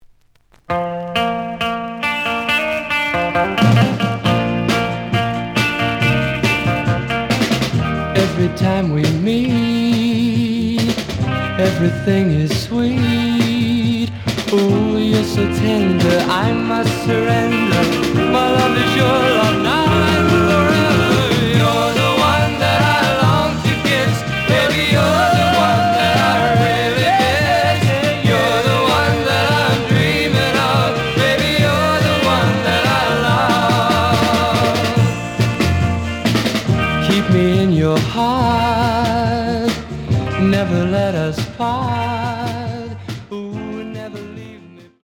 The audio sample is recorded from the actual item.
●Genre: Rock / Pop
Slight edge warp.